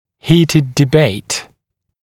[‘hiːtɪd dɪ’beɪt][‘хи:тид ди’бэйт]бурные прения, горячие споры, бурные споры